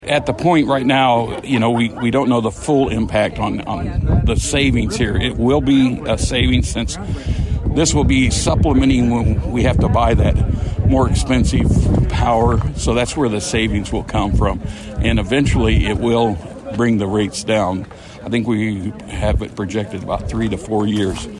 Though officials don’t know how much of a decrease the array will offer to customers, Mayor Mike Topliff says it will partially supplement what power the city is currently purchasing.